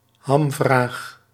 Ääntäminen
IPA : /kɹʌks/